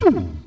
zap.mp3